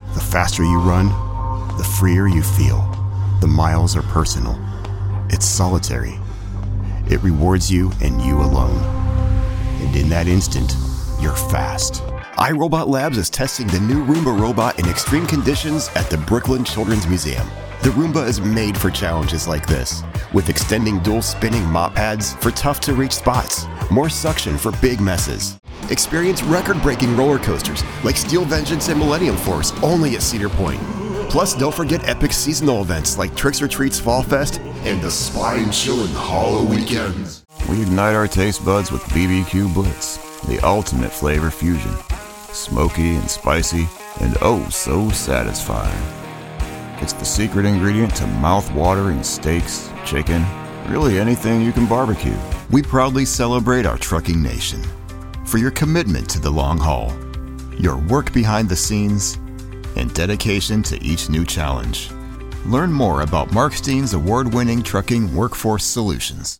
Yng Adult (18-29) | Adult (30-50)